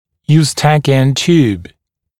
[juːs’tækɪən t(j)uːb][йу:с’тэкиэн т(й)у:б]евстахиева труба